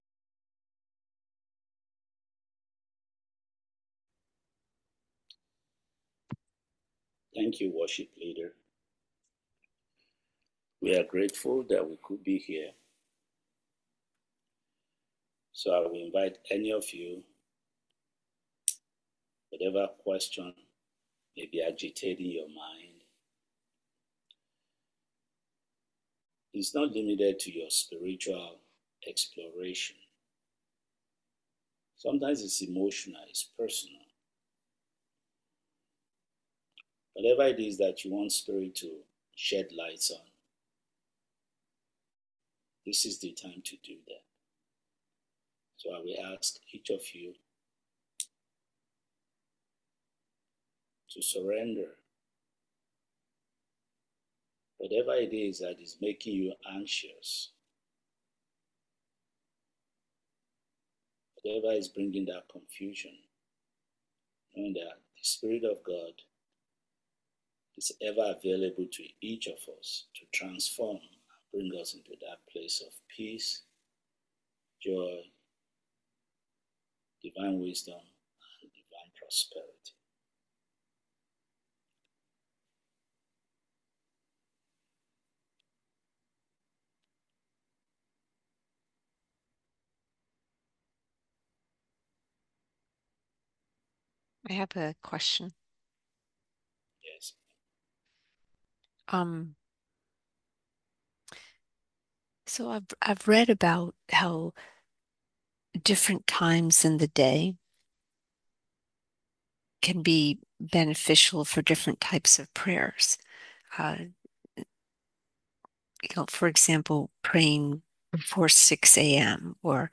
July 2025 Satsang